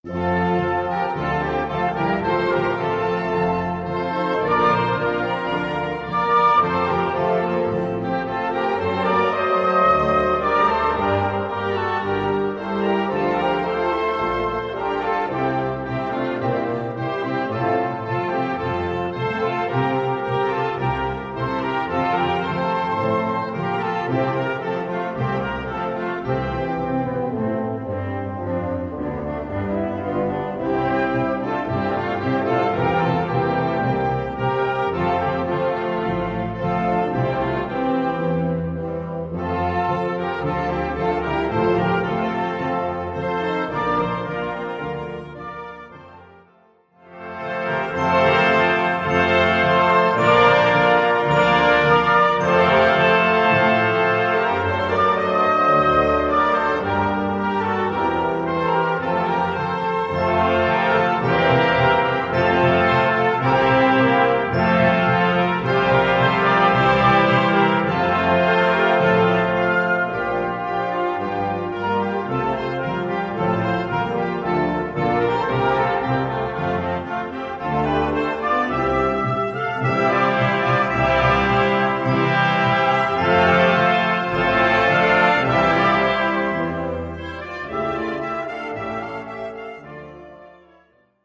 Gattung: Choral
Besetzung: Blasorchester
stimmungsvolle Bearbeitung für Blasorchester